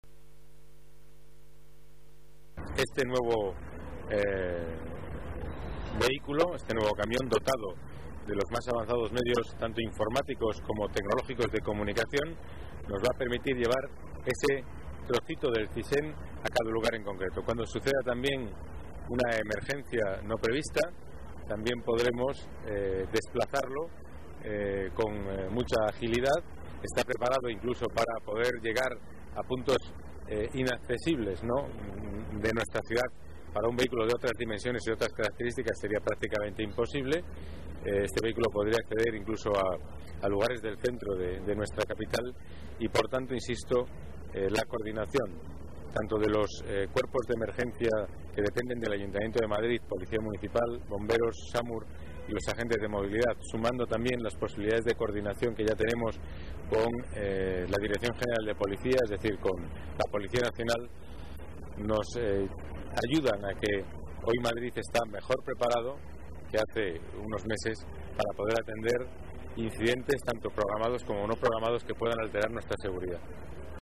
Nueva ventana:Declaraciones delegado Seguridad, Pedro Calvo: Centro de Control Móvil de Policía Municipal